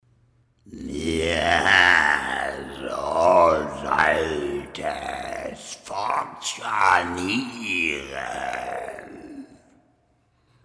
horror stimme
Das beste, Du findest einen Sprecher, der mit seiner Stimme spielen kann. ein wenig Hall drüber und es klingt gruselig, so gruselig, dass Du Dich beim Anhören erschreckst.